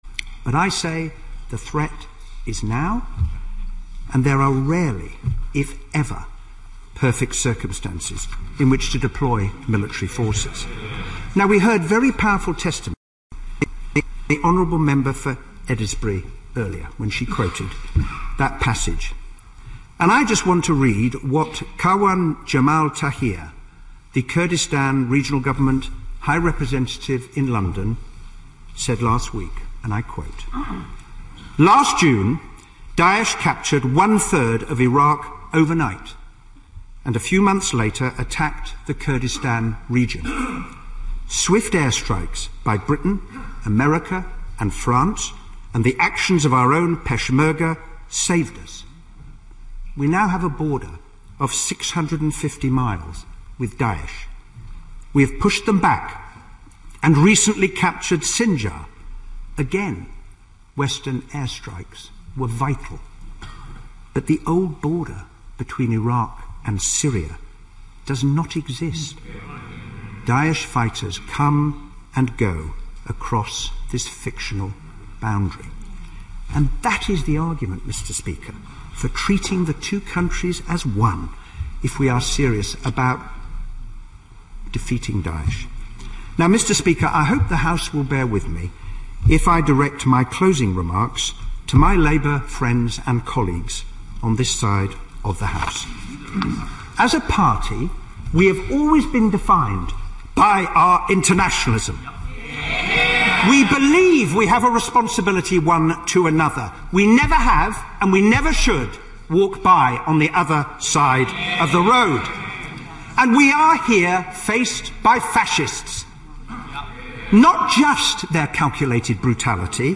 欧美名人演讲 第51期:工党影子大臣希拉里·本恩在议会关于空袭ISIS的演讲(5) 听力文件下载—在线英语听力室